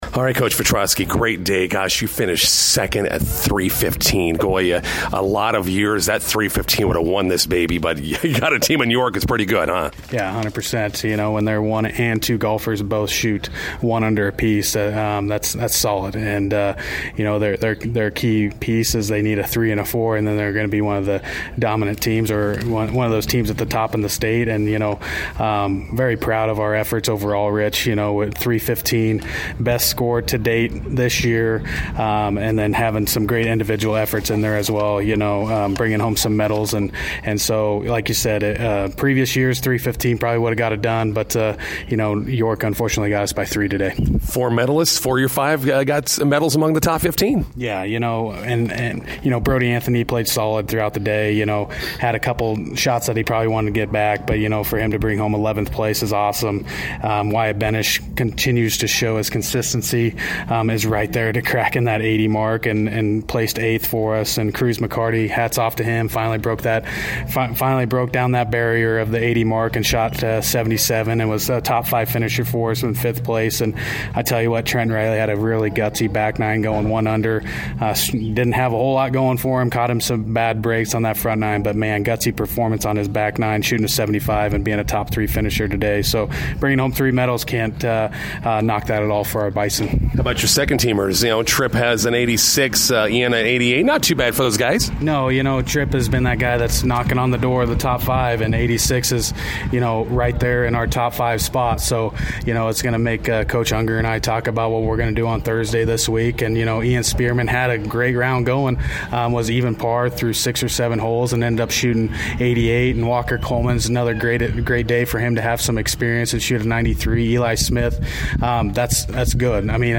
INTERVIEW: Bison golfers finish second to York in the Ron Coleman Invite at Heritage Hills.